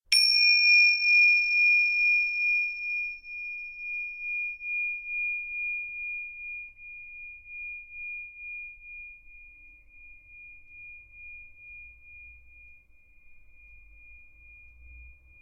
Cloche1
cloche.mp3